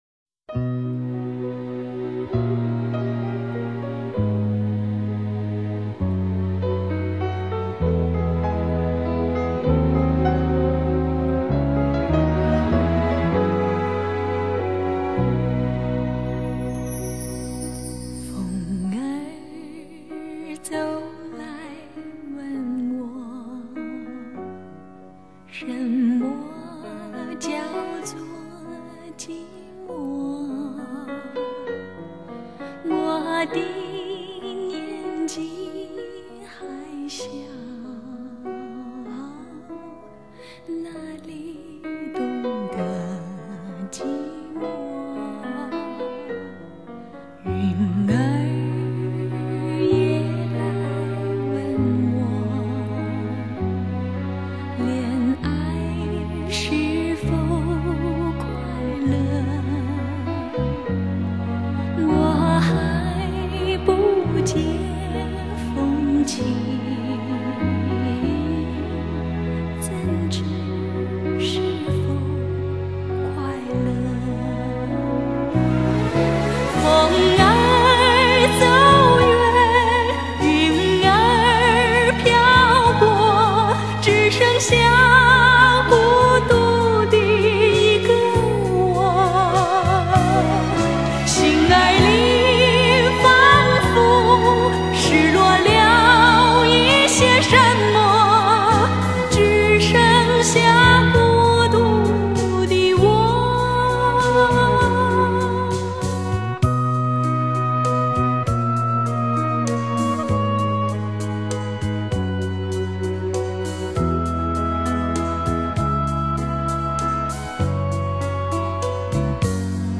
该专辑以原版录音再经过最新技术作后期处理，声音宽阔清澈。